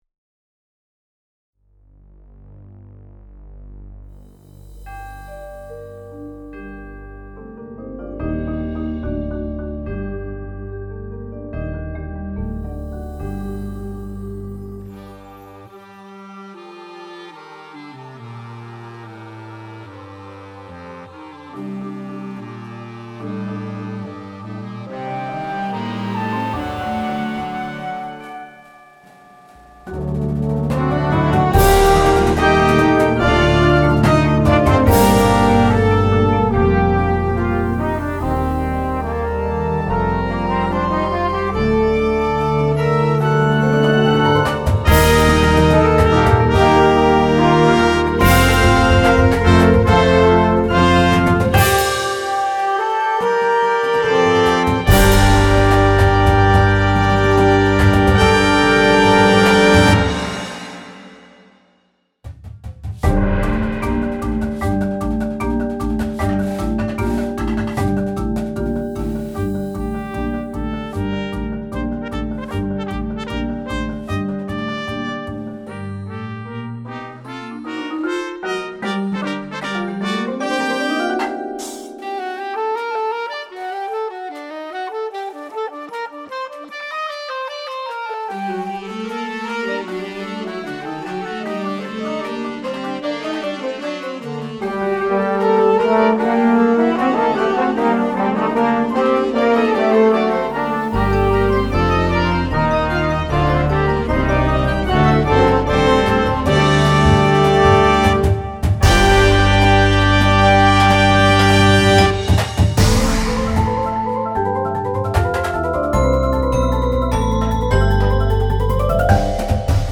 Mvt. 1 (LIVE)